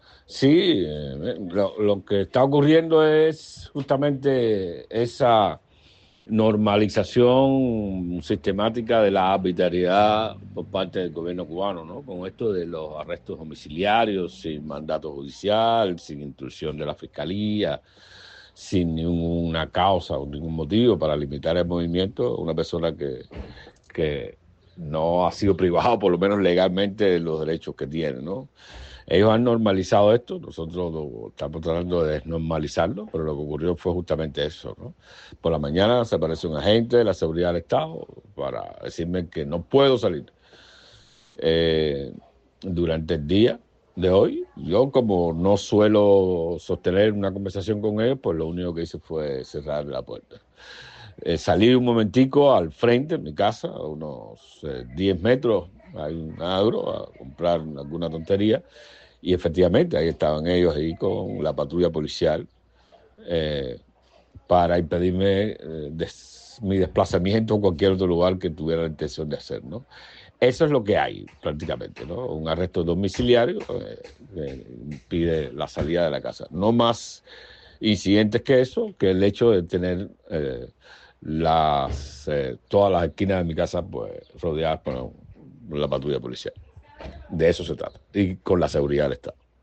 Declaraciones de Manuel Cuesta Morúa